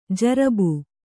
♪ jarabu